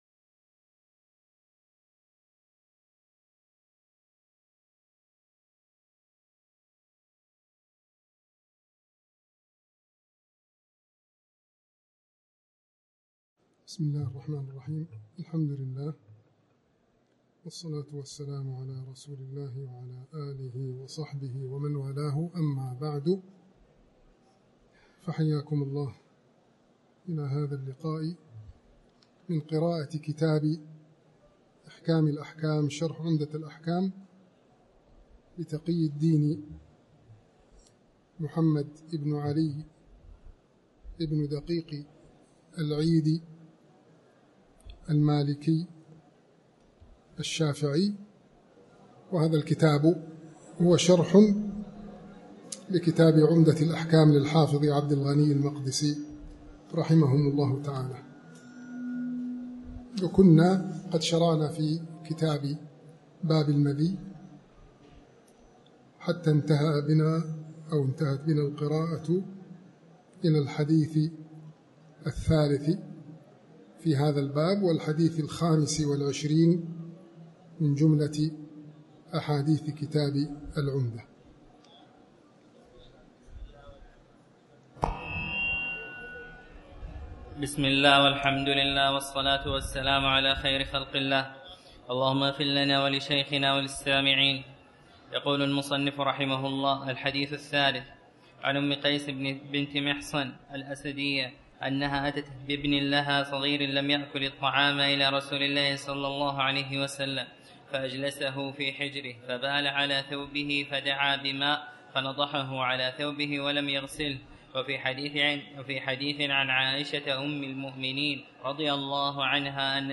تاريخ النشر ٨ صفر ١٤٤٠ هـ المكان: المسجد الحرام الشيخ